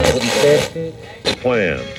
120BPMRAD1-R.wav